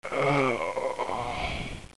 Звук из CS 1.6 смерть 3